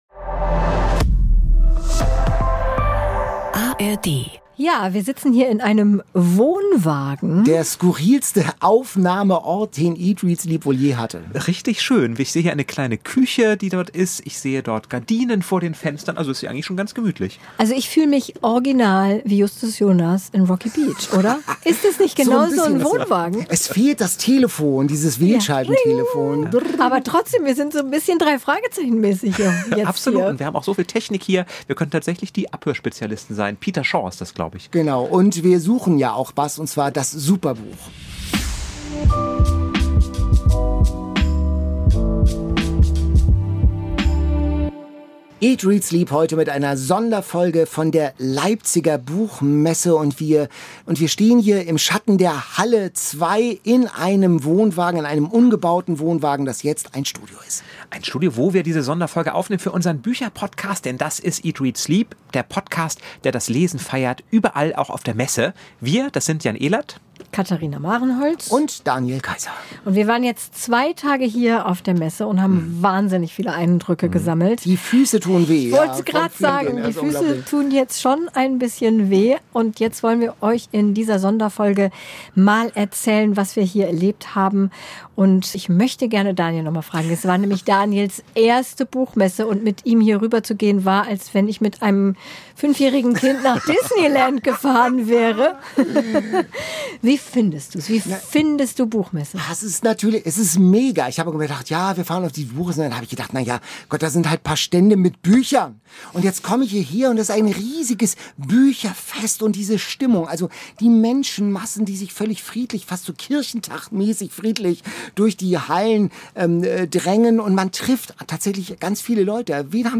In dieser Sonderfolge berichten sie von ihren Erlebnissen und Begegnungen auf der Leipziger Buchmessen. Bonus: Interview mit Bestsellerautorin Gaea Schoeters.